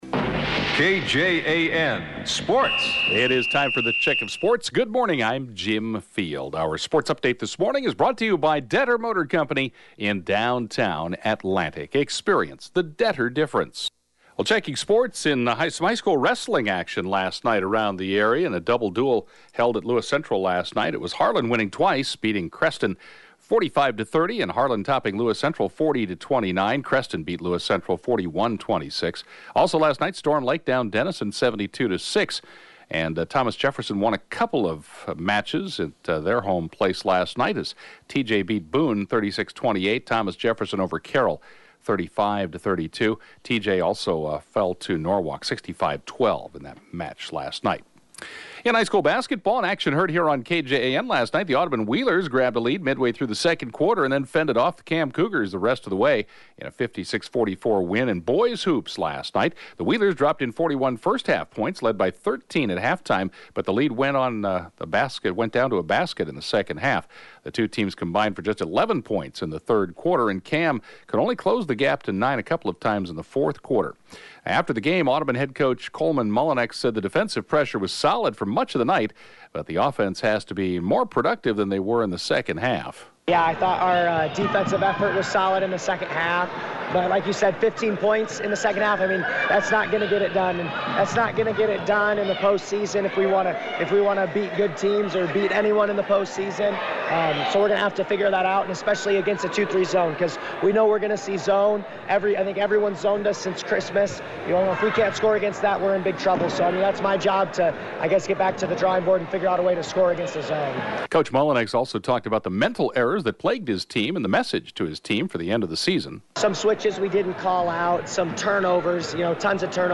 (Podcast) KJAN Morning Sports report, 5/3/2017